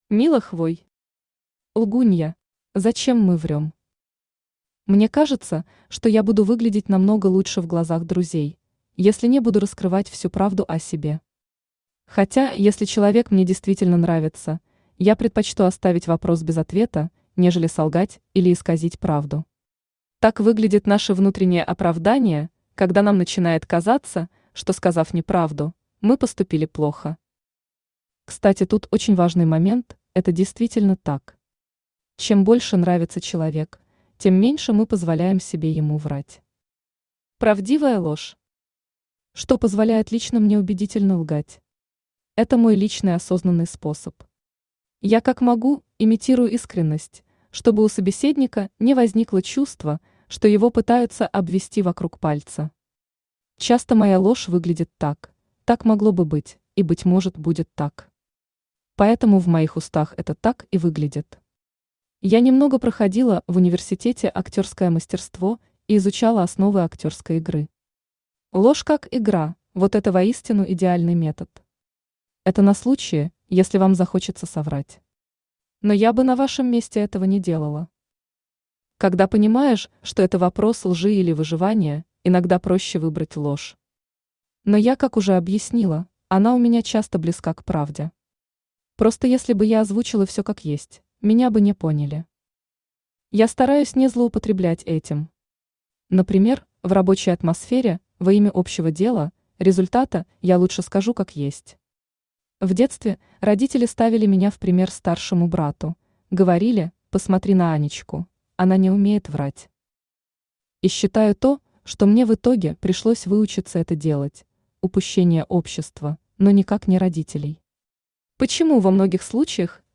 Аудиокнига Лгунья | Библиотека аудиокниг
Aудиокнига Лгунья Автор Мила Хвой Читает аудиокнигу Авточтец ЛитРес.